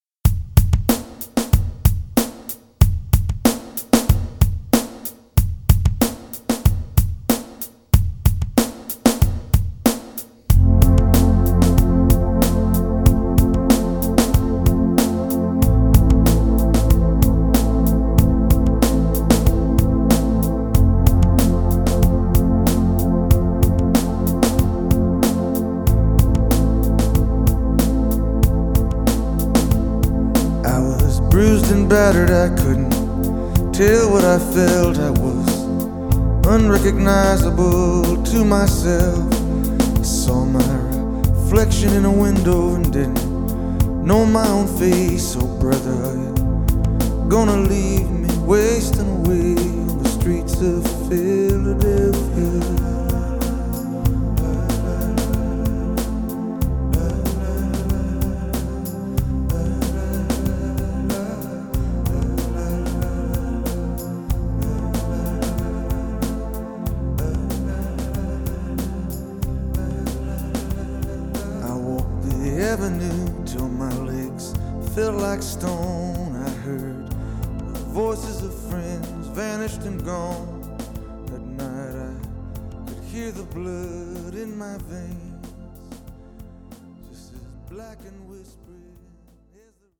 searingly touching